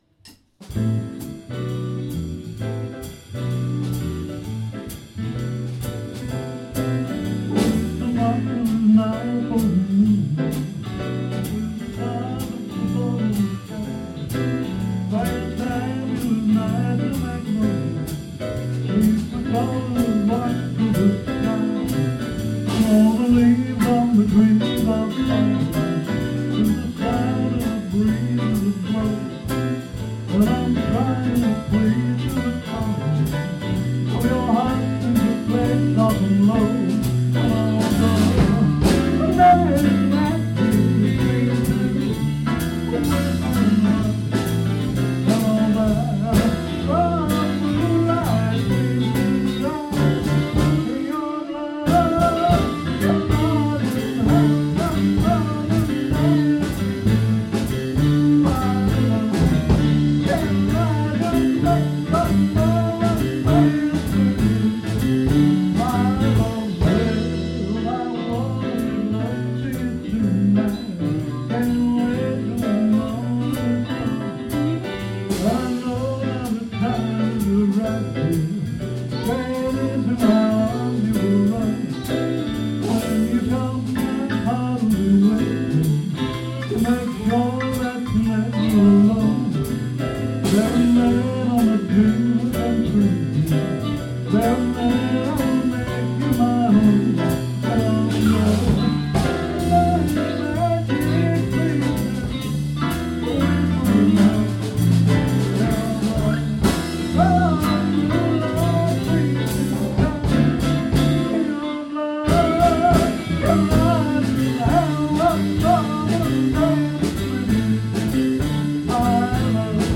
Here I am playing
bass